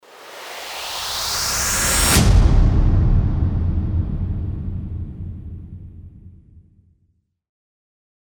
FX-716-RISING-IMPACT
FX-716-RISING-IMPACT.mp3